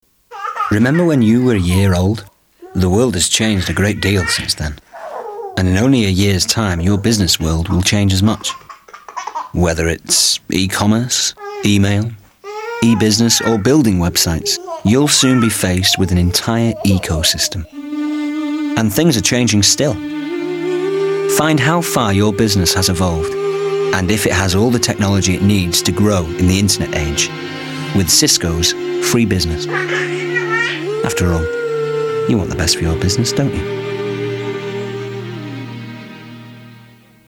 Cisco - Ad for something, I'm not sure what